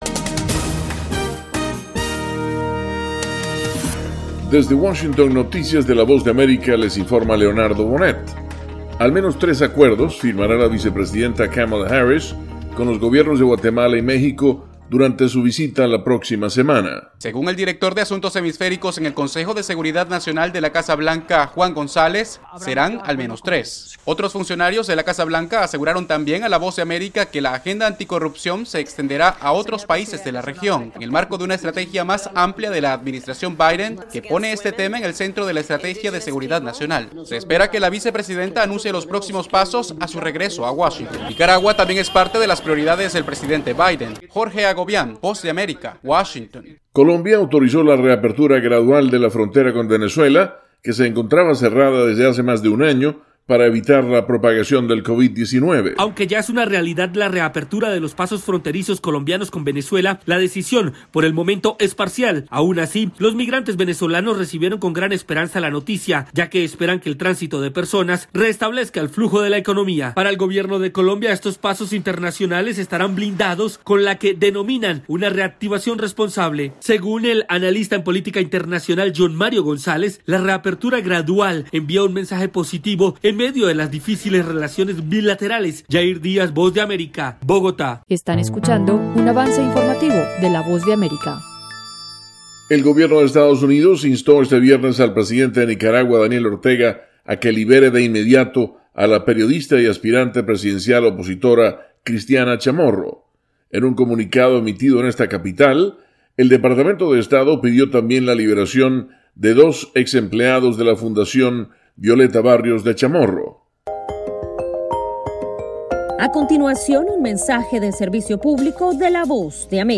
Avance Informativo - 7:00 PM